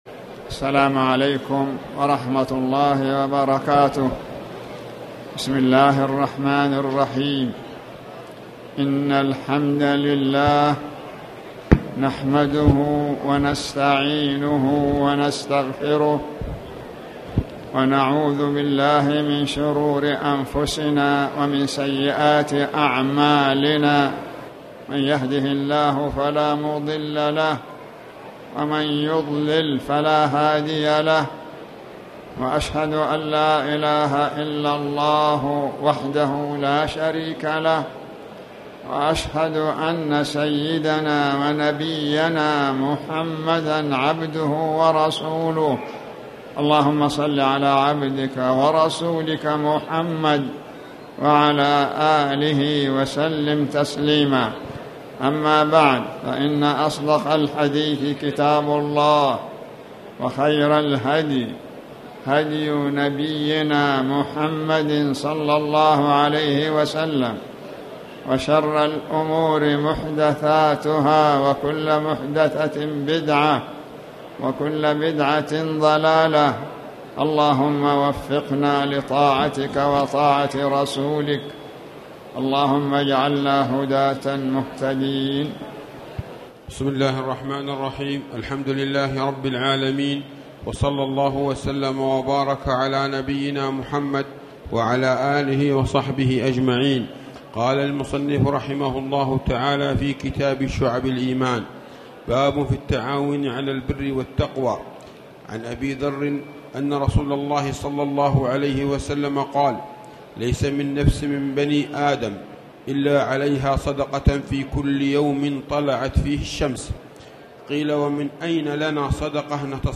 تاريخ النشر ١٠ شوال ١٤٣٨ هـ المكان: المسجد الحرام الشيخ